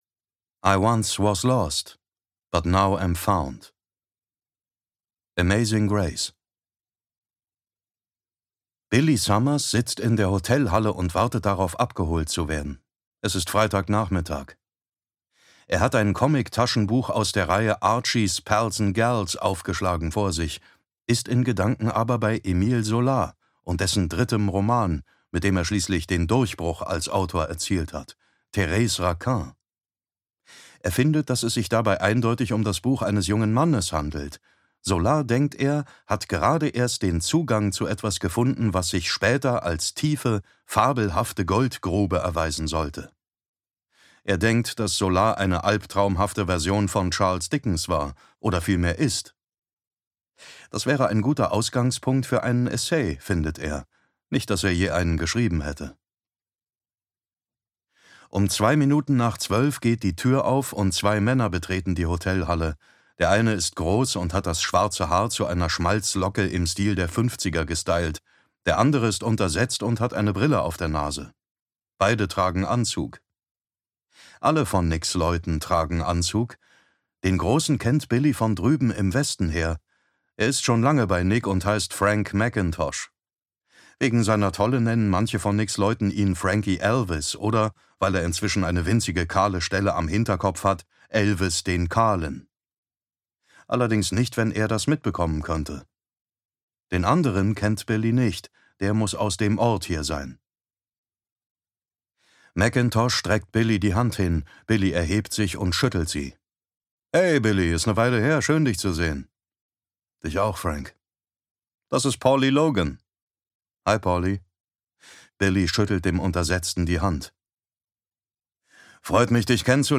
Billy Summers (DE) audiokniha
Ukázka z knihy